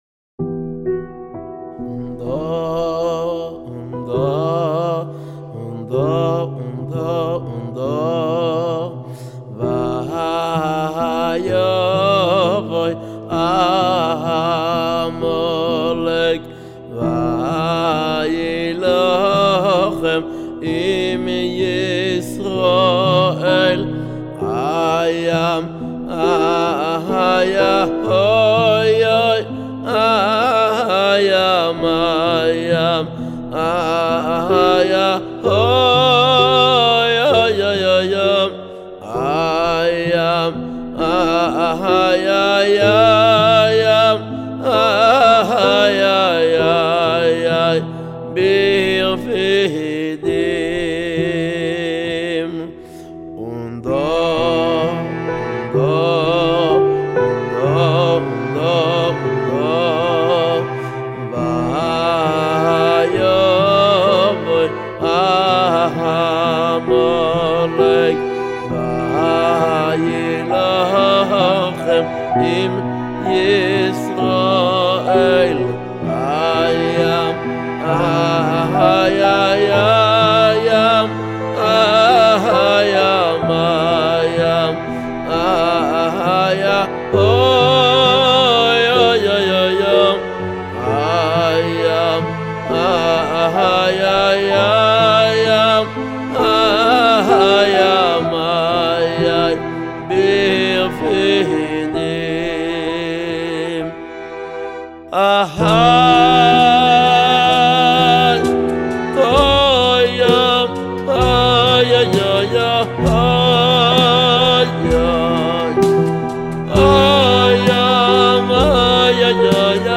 ראשי > שירי ברסלב > ויבוא עמלק – הנוסח המדויק
בליווי מוזיקלי.